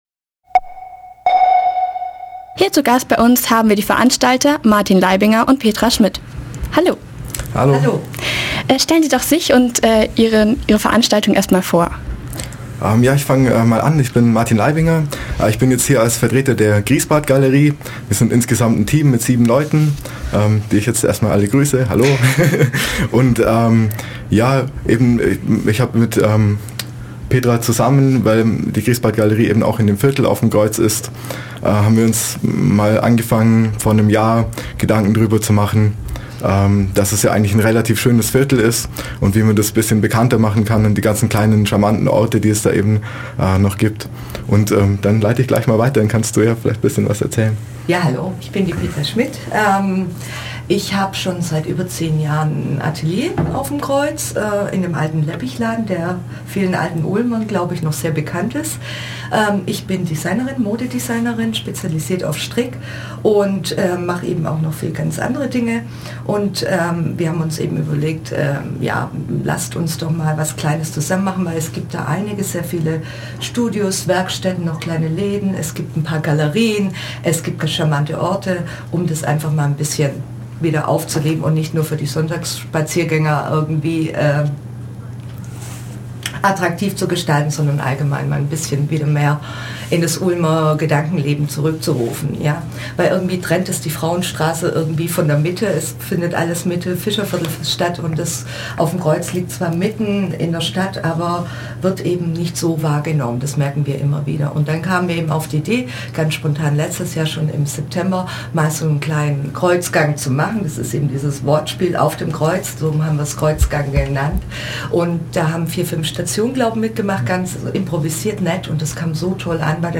Radio
interview_kreugaenge_2.mp3